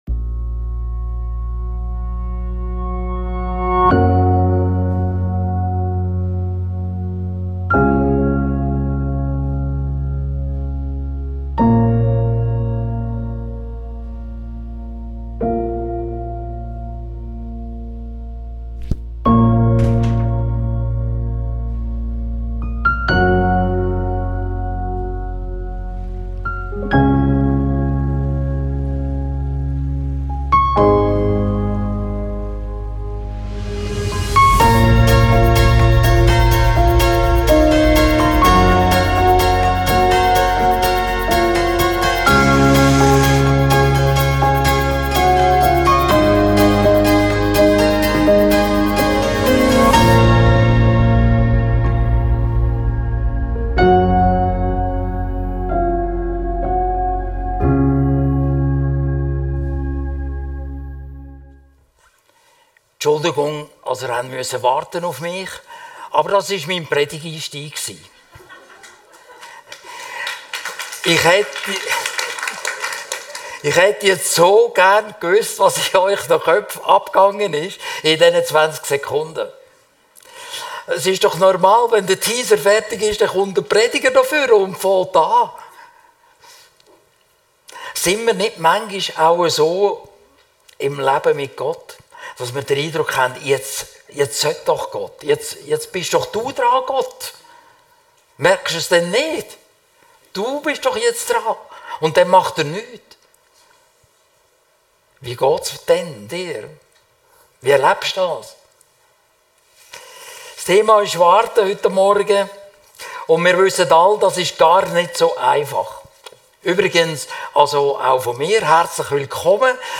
Coming soon! Christsein in den Warteschlaufen des Lebens - seetal chile Predigten